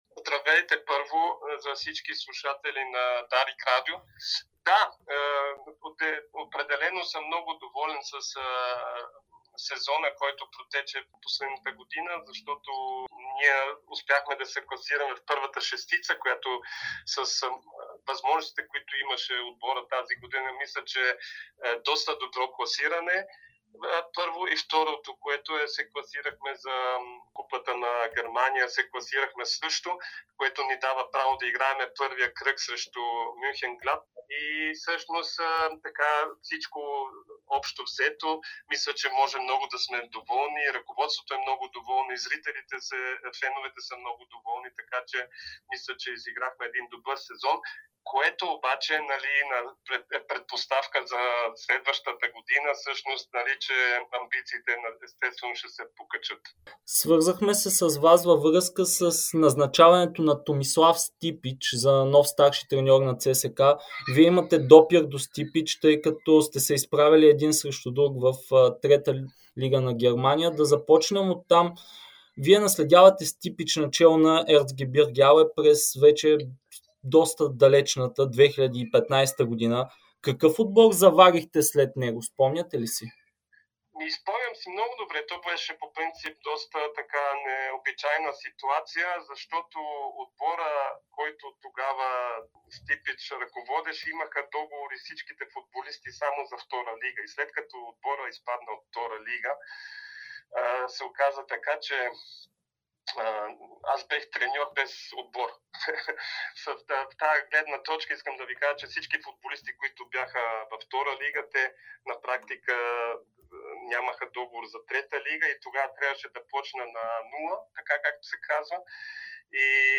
ексклузивно интервю пред Дарик радио и dsport